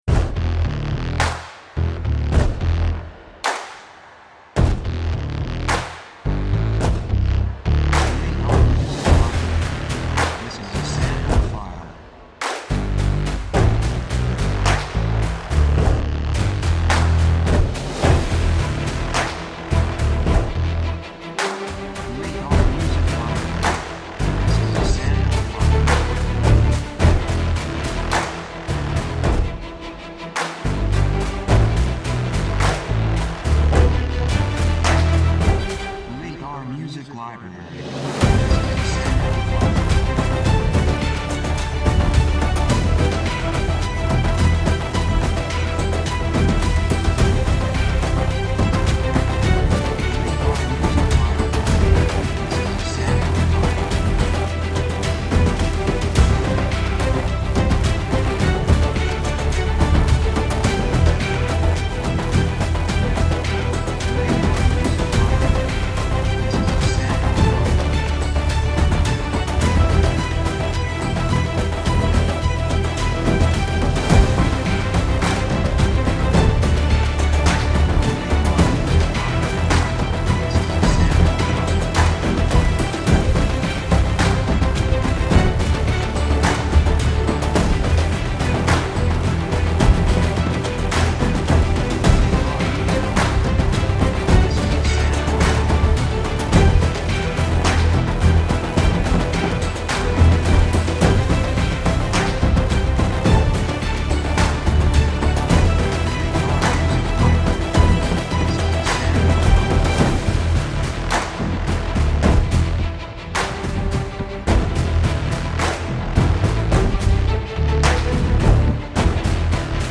雰囲気激しい, 壮大, 決意, 喜び
曲調ニュートラル
楽器オーケストラ, パーカッション, ボーカル, 手拍子
サブジャンルシネマティック, オーケストラハイブリッド
テンポやや速い